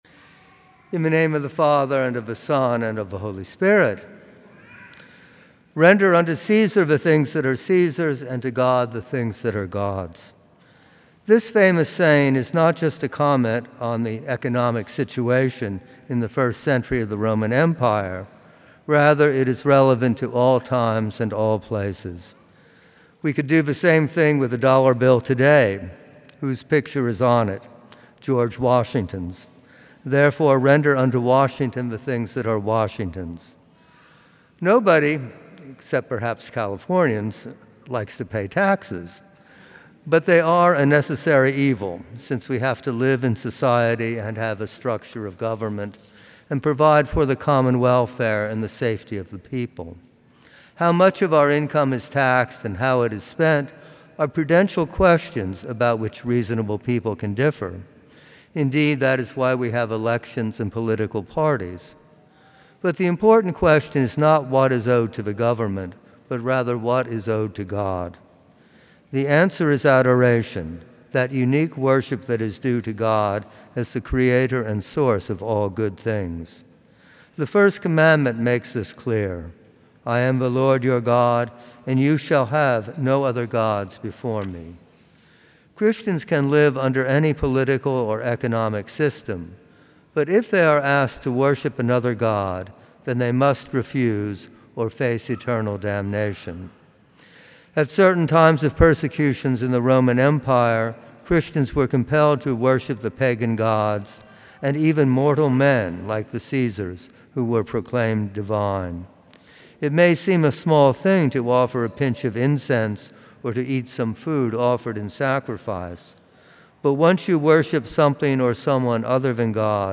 From Series: "Homilies"
Homilies that are not part of any particular series.